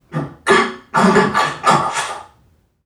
NPC_Creatures_Vocalisations_Robothead [29].wav